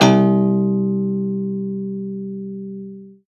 53p-pno02-C0.wav